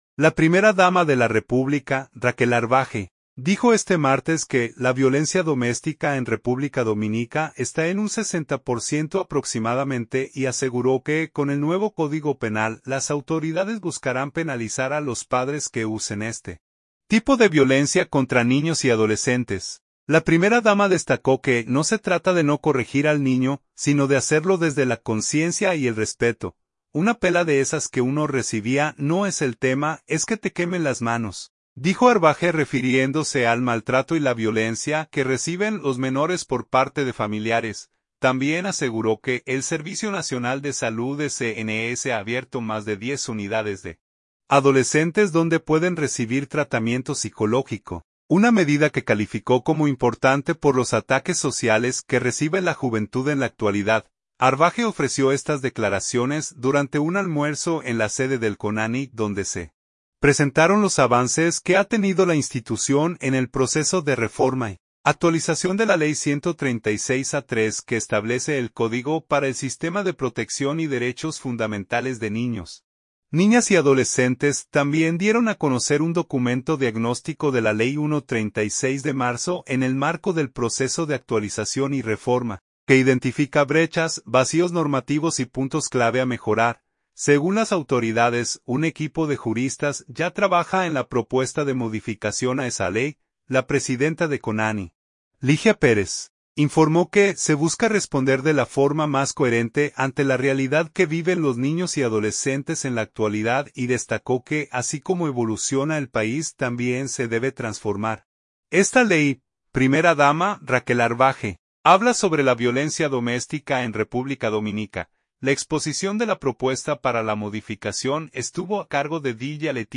Arbaje ofreció estas declaraciones durante un almuerzo en la sede del CONANI donde se presentaron los avances que ha tenido la institución en el proceso de reforma y actualización de la ley 136-03 que establece el Código para el Sistema de Protección y Derechos fundamentales de Niños, Niñas y Adolescentes.
Primera dama, Raquel Arbaje, habla sobre la violencia doméstica en República Dominica.